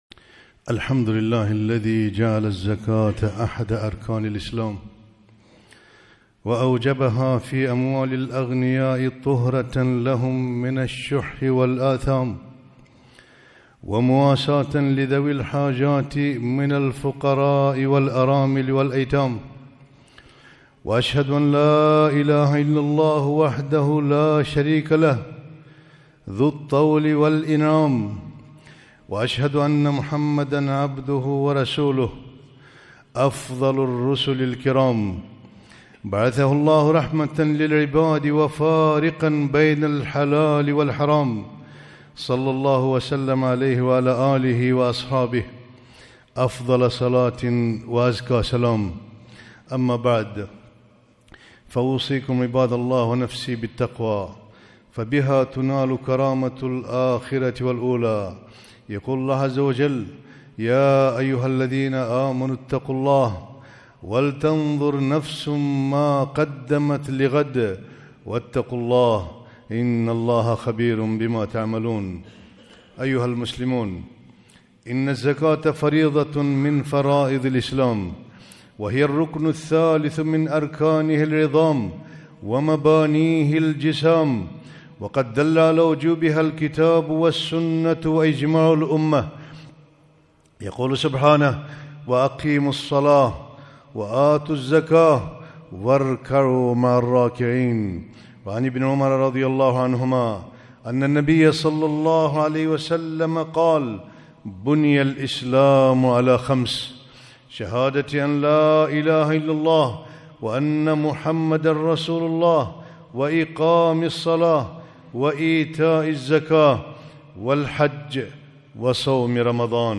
خطبة - الزكاة تزكية وطهارة